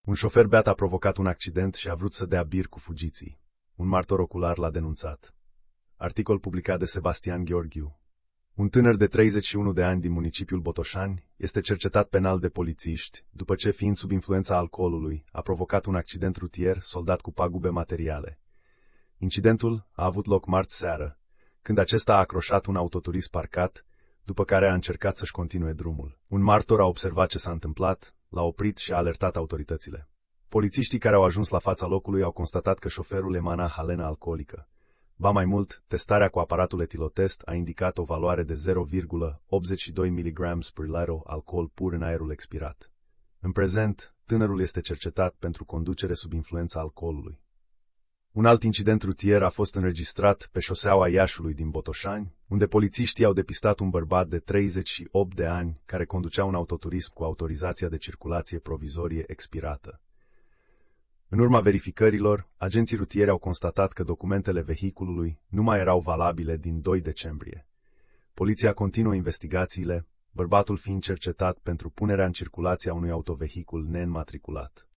Știri Audio